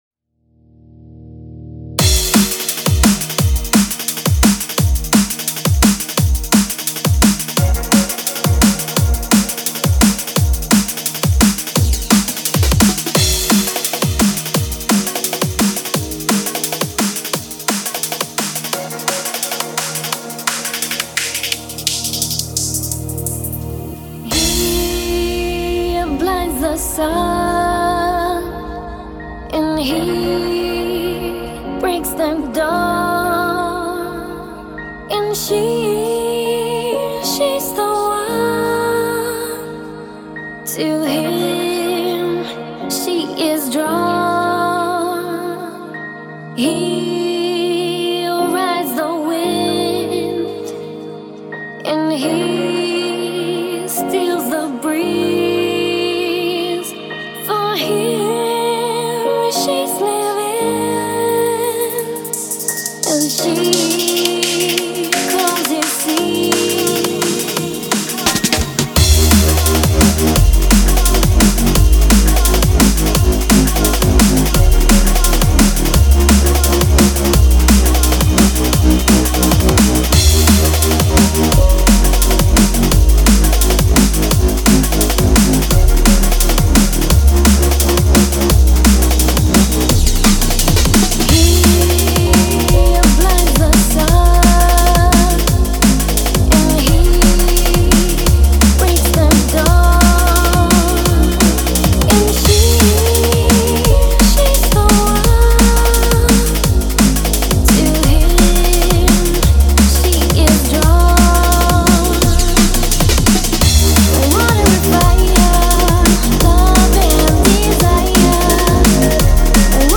Жанр: Drum&Bass